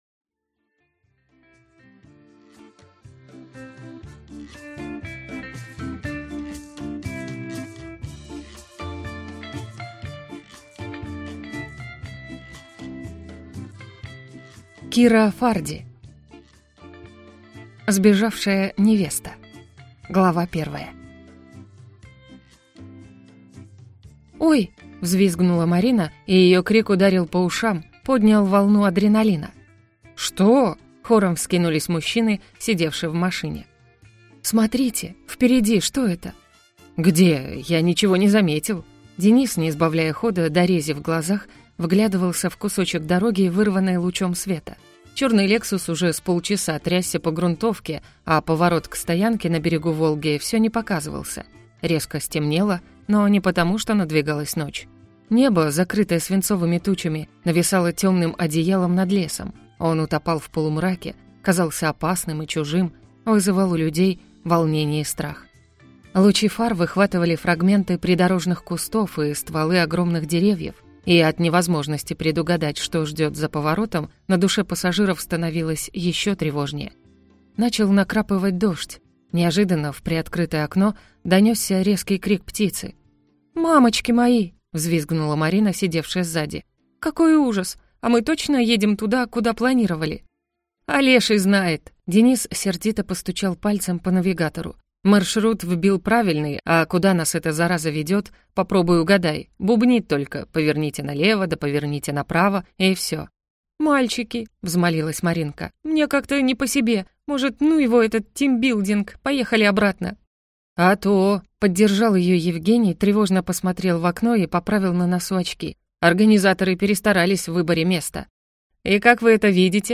Аудиокнига Сбежавшая невеста | Библиотека аудиокниг